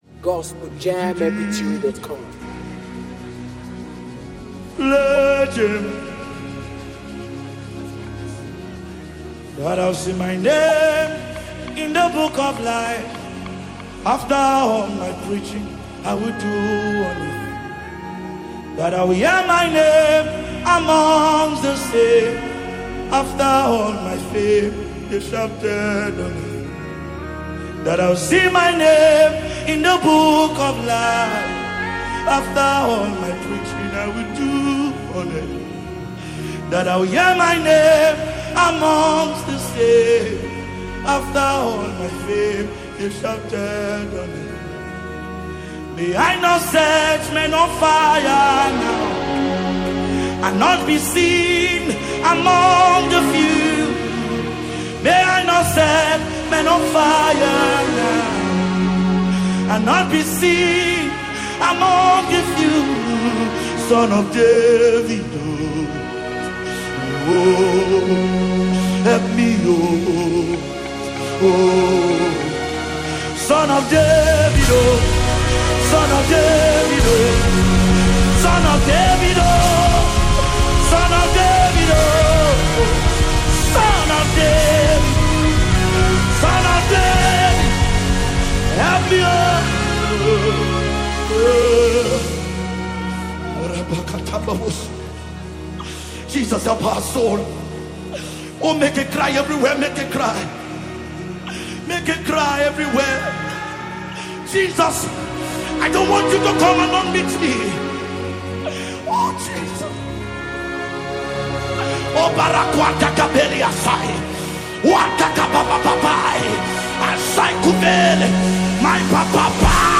contemporary gospel worship song